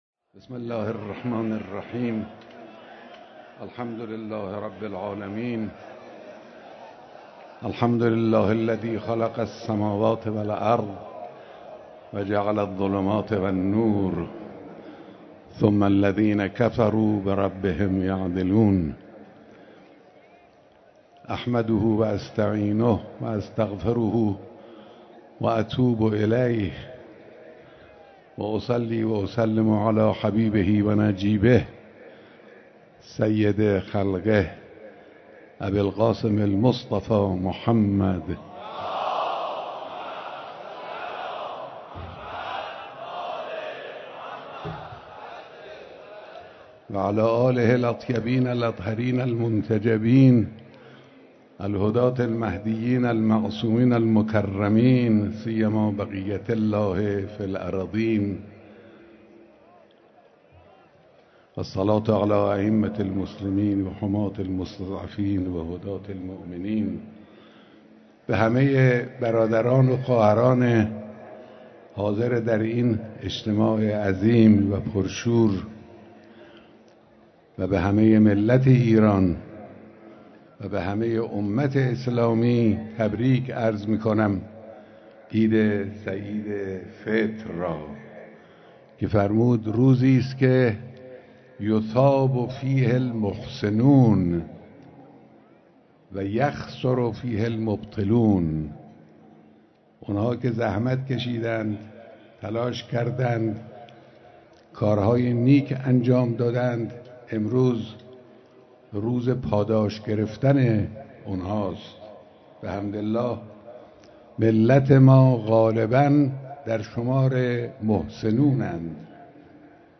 بیانات در خطبه‌های نماز عید سعید فطر